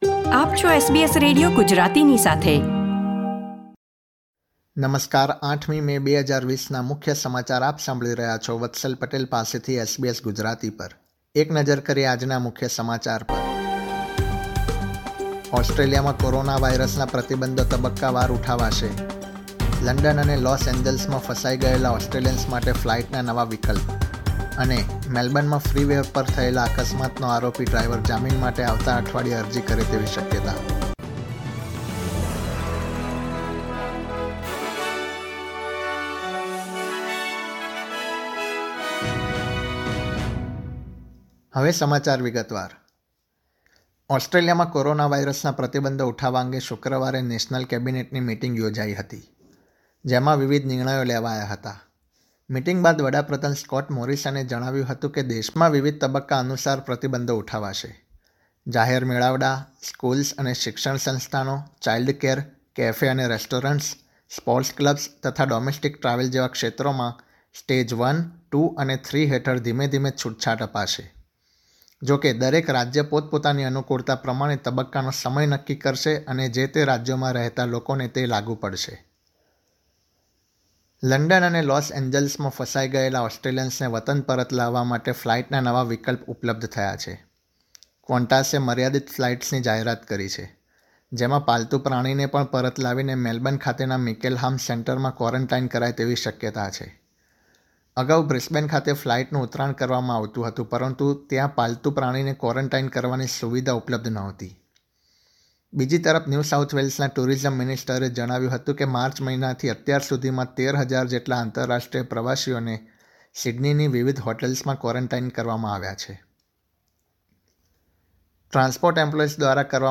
SBS Gujarati News Bulletin 8 May 2020